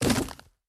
drop.ogg